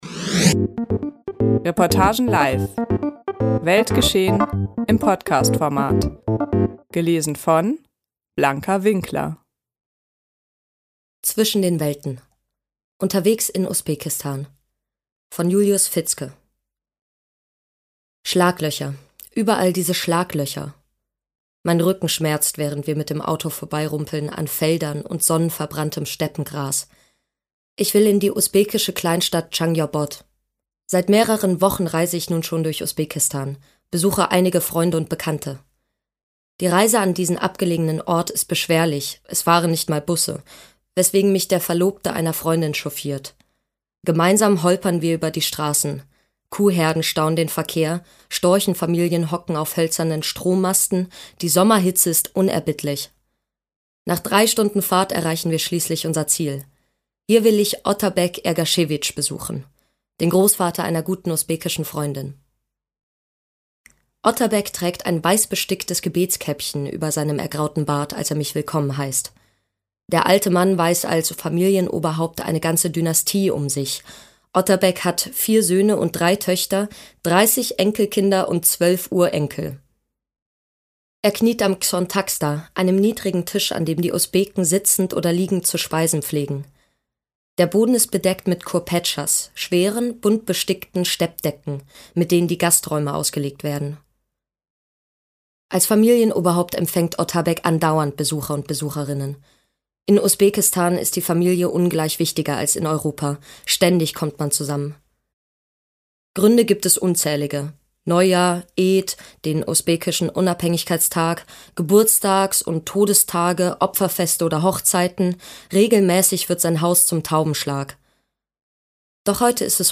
Zwischen den Welten – unterwegs in Usbekistan ~ Podcast Reportagen live.